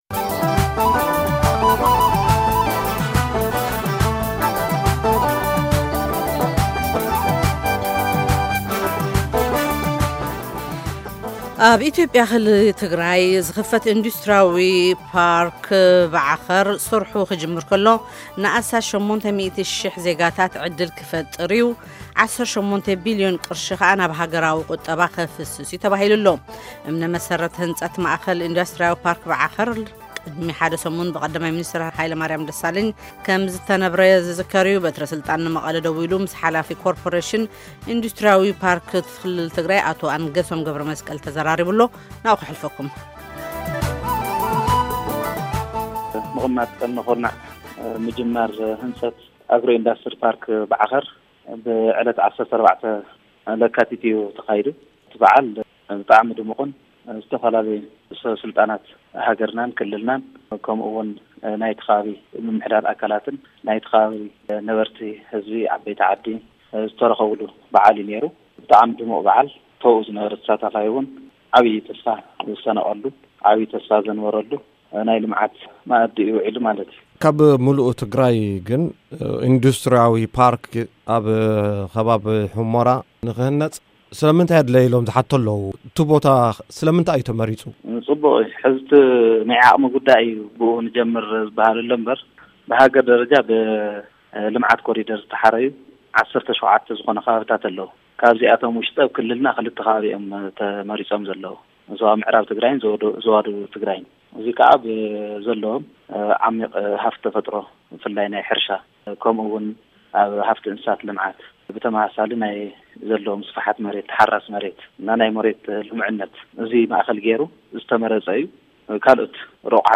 ሙሉእ ቃለ ምልልስ